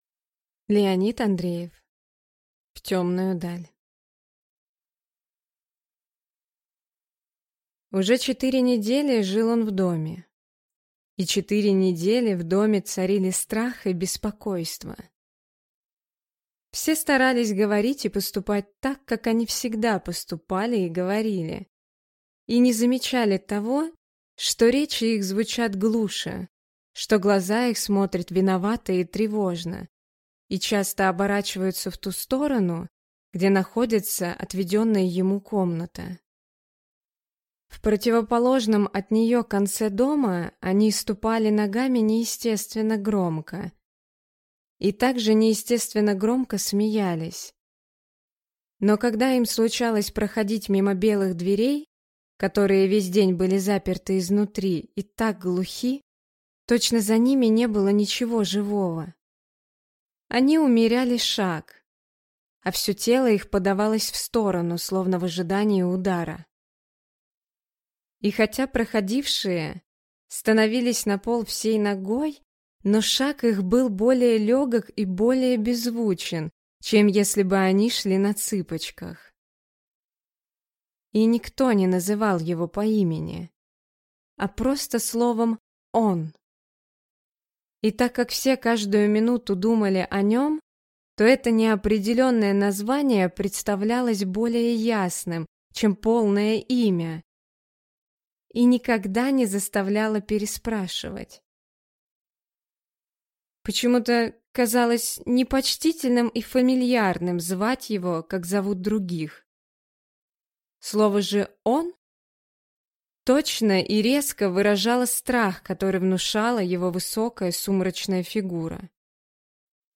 Аудиокнига В темную даль | Библиотека аудиокниг